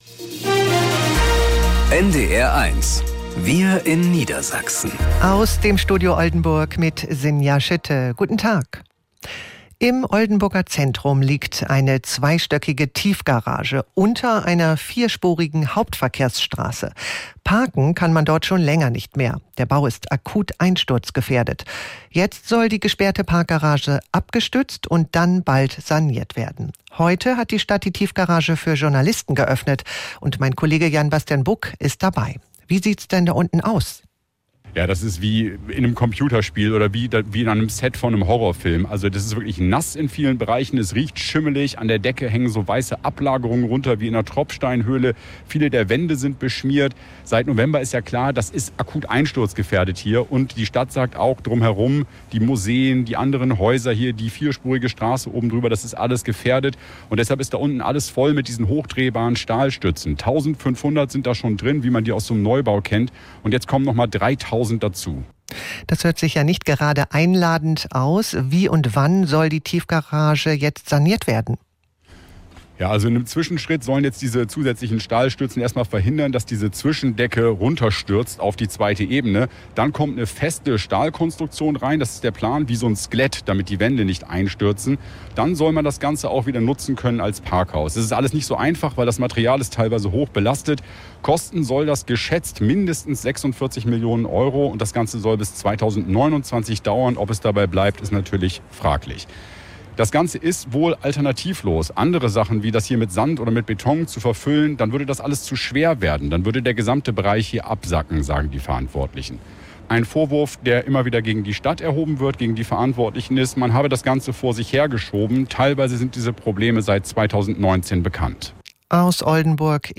Nachrichten aus dem Studio Oldenburg